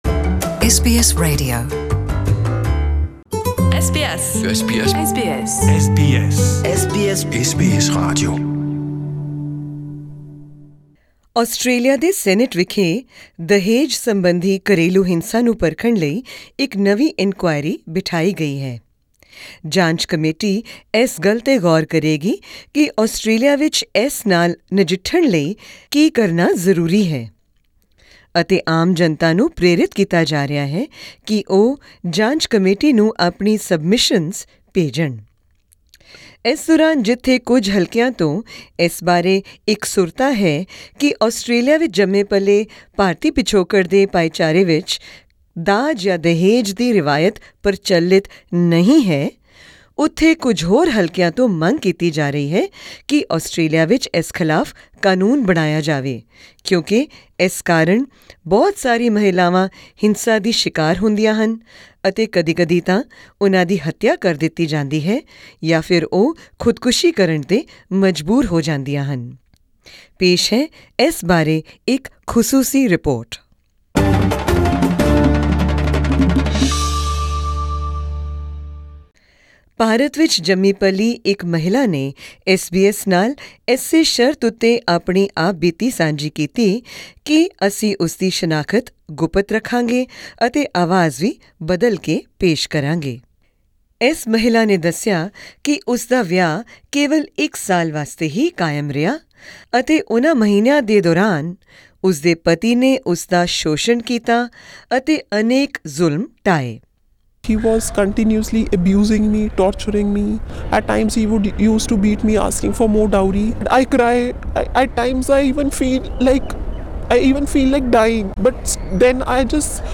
An Indian-born woman, who agreed to speak with SBS if her voiced was disguised, says she plans to share the darkest memories of her year-long marriage with Australia's inquiry into dowry abuse.